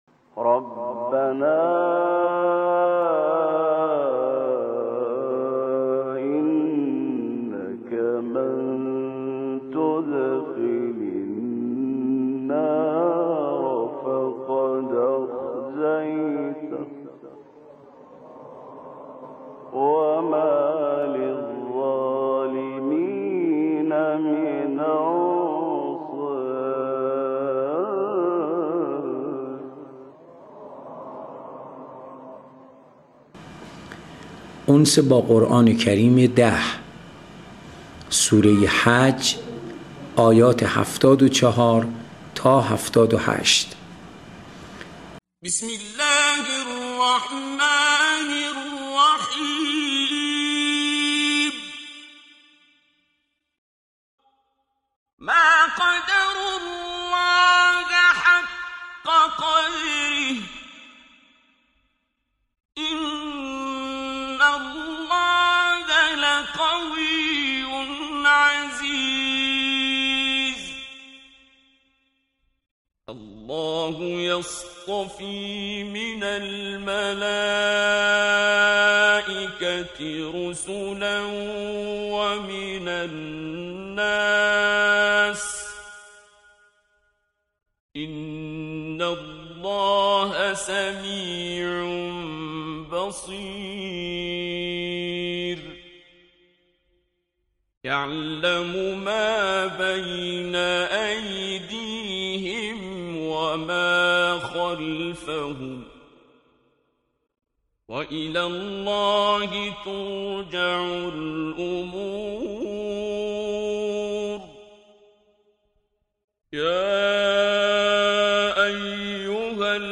قرائت آیات آیات 75 الی 78 سوره حج